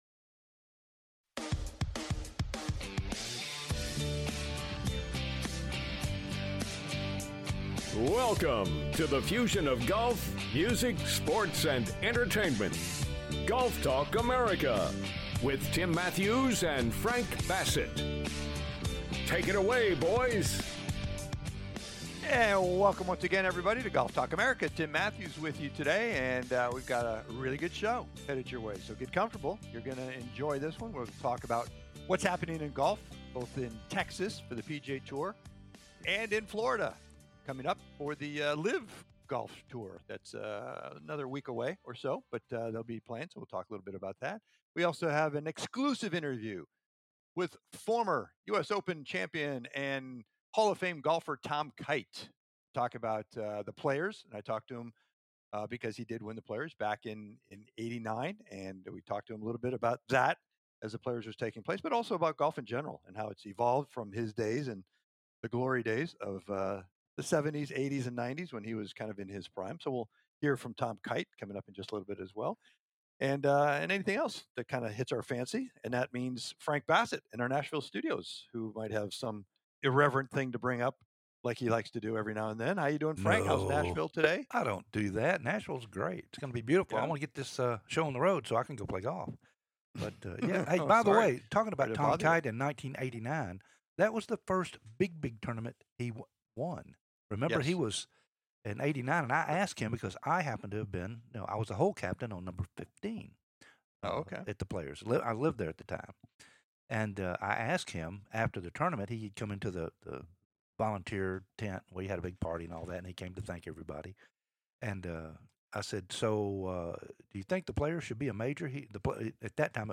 Special interview with PGA TOUR CHAMPION TOM KITE...